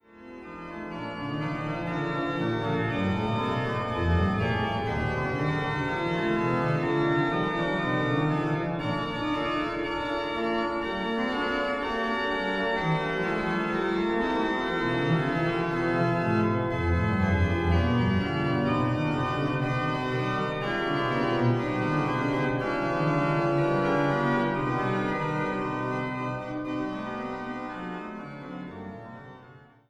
Walcker-Orgel